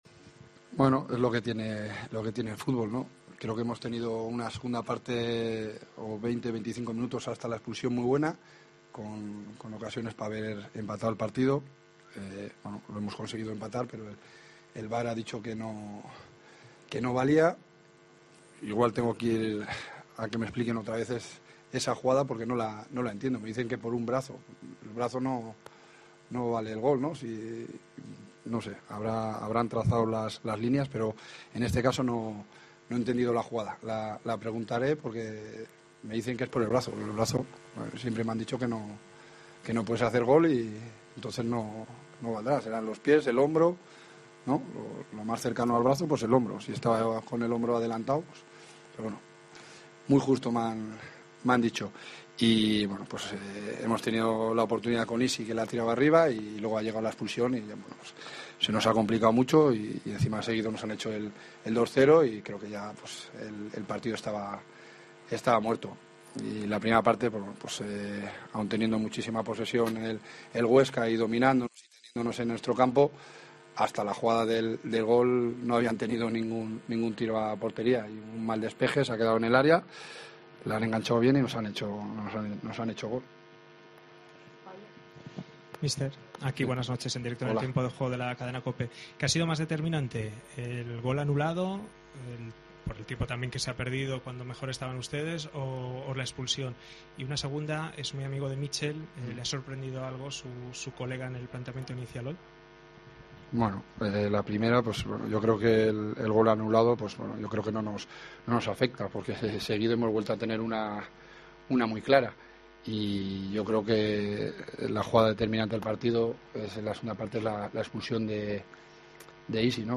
AUDIO: Escucha aquí el postpartido con declaraciones de Jon Pérez Bolo y Míchel Sánchez